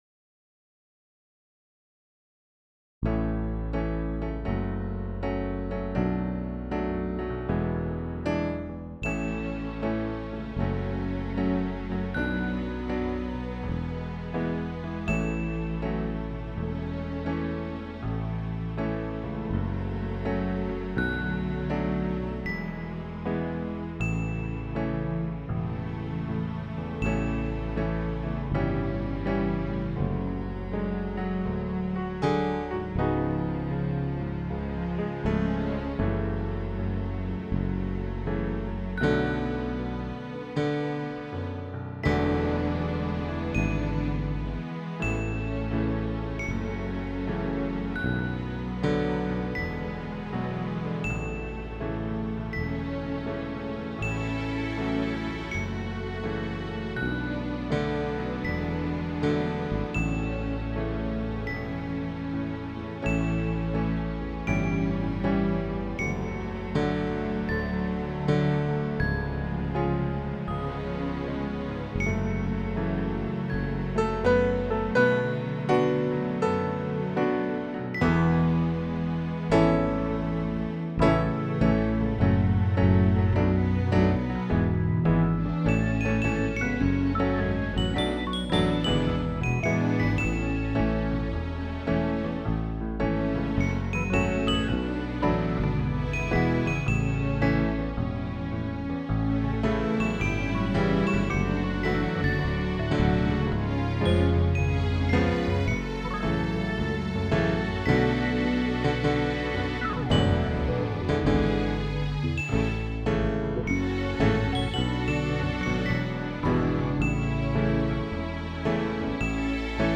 Accompaniment  (Copyright)